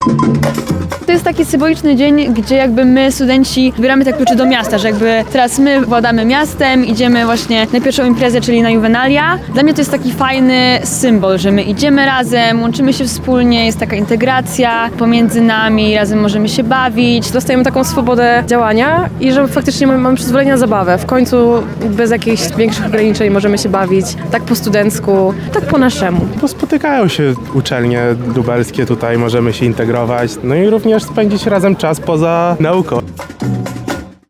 Władze Lublina uroczyście przekazały klucze od miasta studentom. Następnie, jak co roku, ulicami przeszedł korowód.